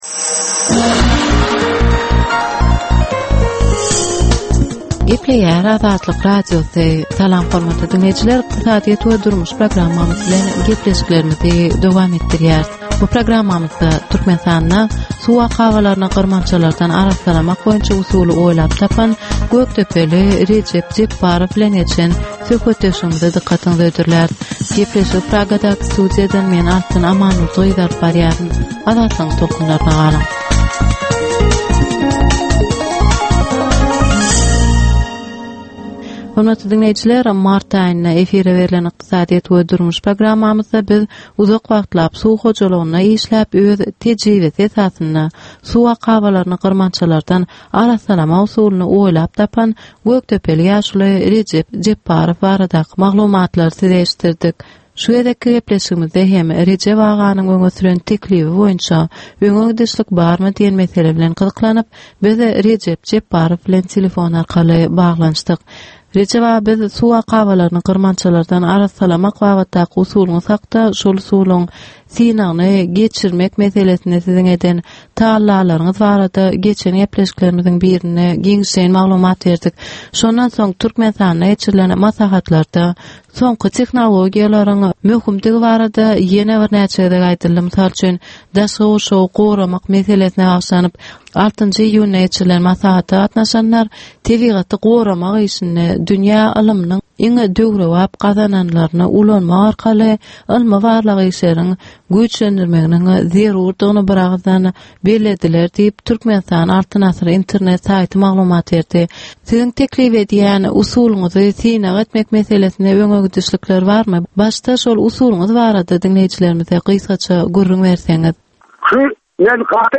Türkmenistanyň ykdysadyýeti bilen baglanyşykly möhüm meselelere bagyşlanylyp taýýarlanylýan ýörite gepleşik. Bu gepleşikde Türkmenistanyň ykdysadyýeti bilen baglanyşykly, şeýle hem daşary ýurtlaryň tejribeleri bilen baglanyşykly derwaýys meseleler boýnça dürli maglumatlar, synlar, adaty dinleýjileriň, synçylaryň we bilermenleriň pikirleri, teklipleri berilýär.